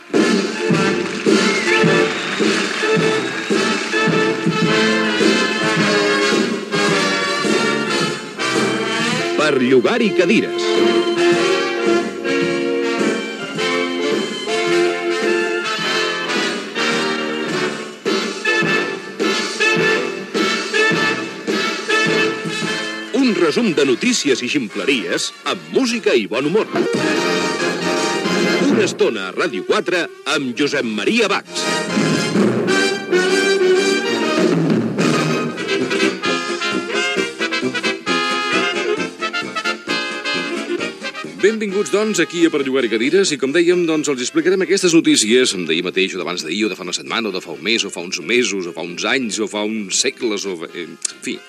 Careta del programa
Entreteniment